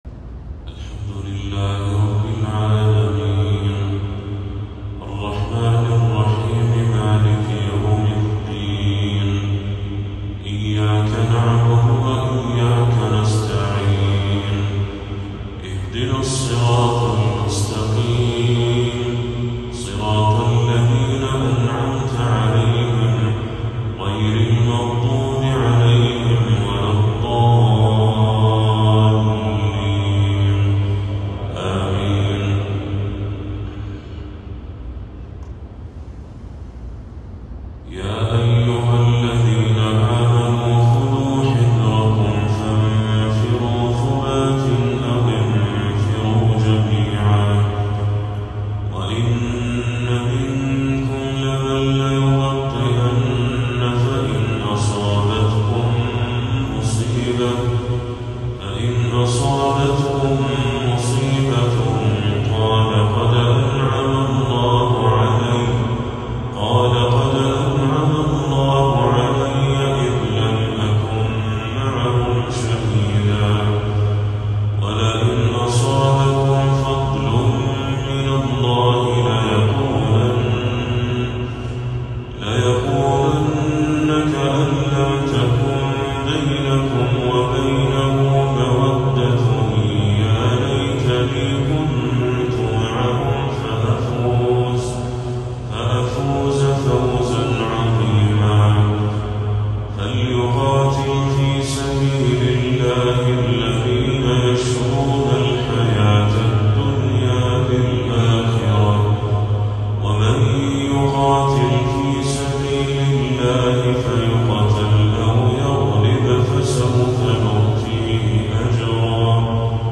تلاوة عذبة من سورة النساء للشيخ بدر التركي | عشاء 3 ربيع الأول 1446هـ > 1446هـ > تلاوات الشيخ بدر التركي > المزيد - تلاوات الحرمين